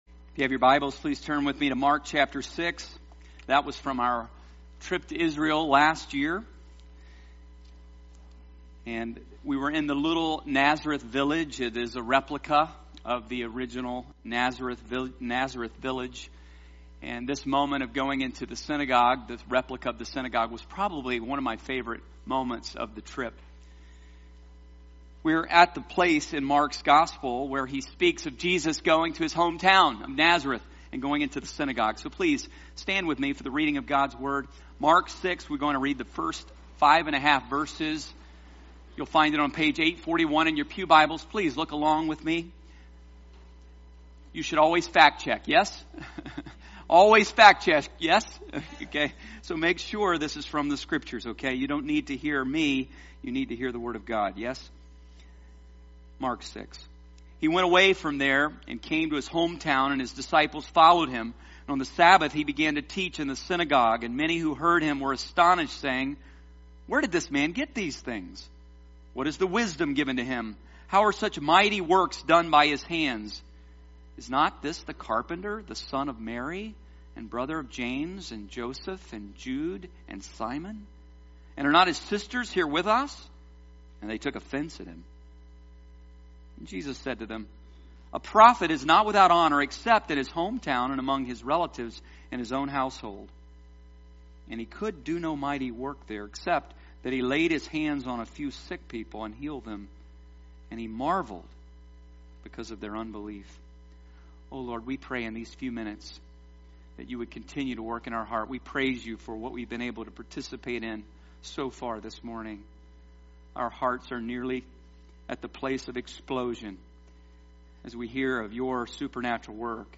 Sermon Series on the book of Mark